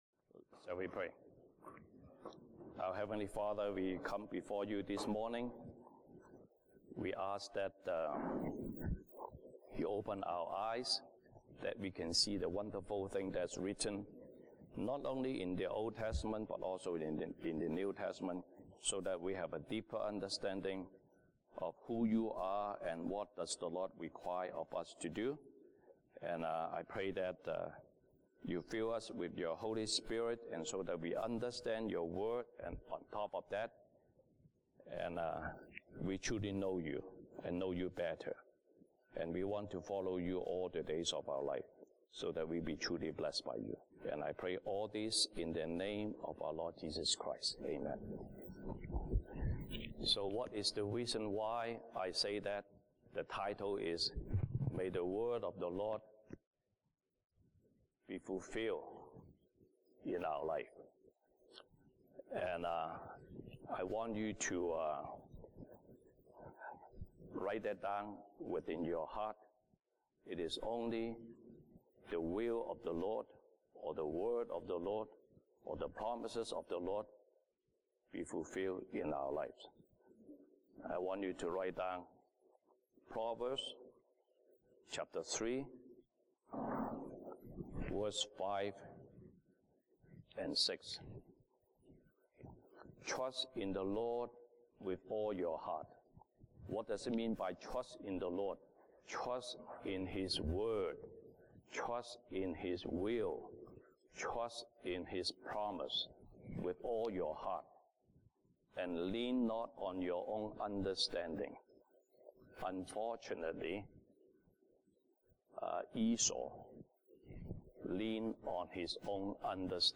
西堂證道 (英語) Sunday Service English: May God’s Word fulfill in our life